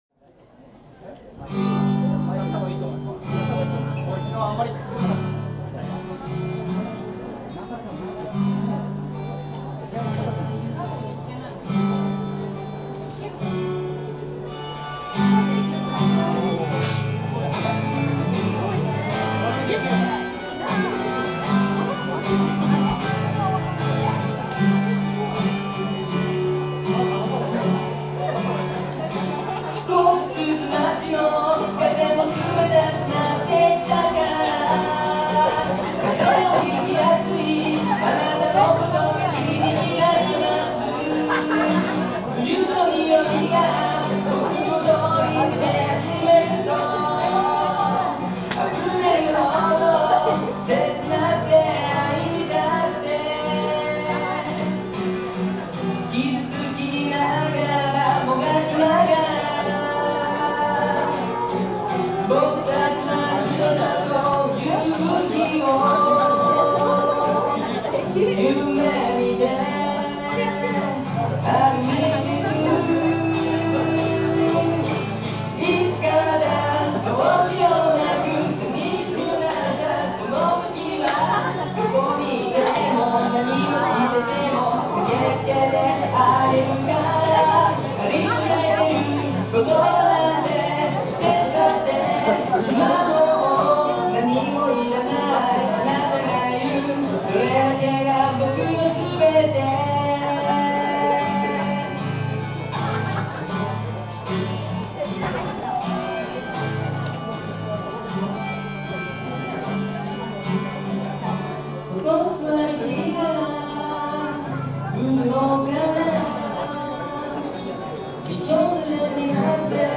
【本番（ＹＭＣＡ祭ライブ音源）】
音質は低いですが、少しでもライブの雰囲気が伝われば嬉しいです(^^)。
喉カラカラだったけど頑張って歌いました(^^;)。
最後の方で気を抜いてしまい、ミス連発．．．
使用したギター：Taylor 810
使用したハープ：Tombo Major Boy D